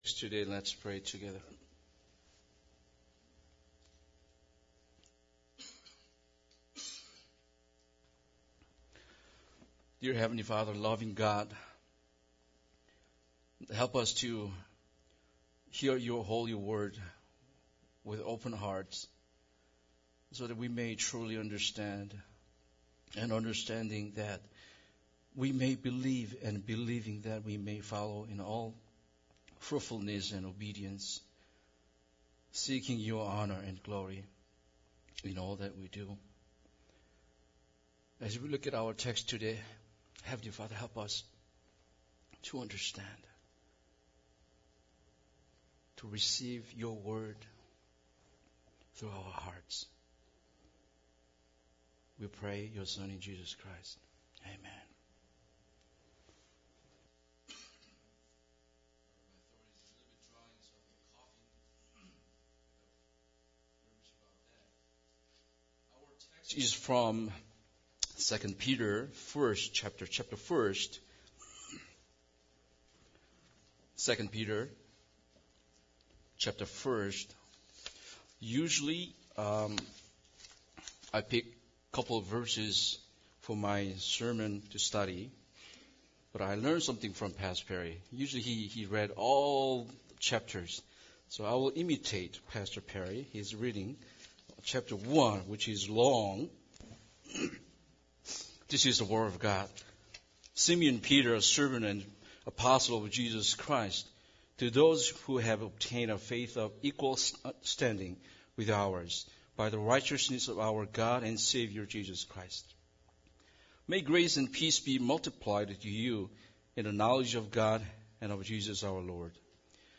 2 Peter 1 Service Type: Sunday Service Bible Text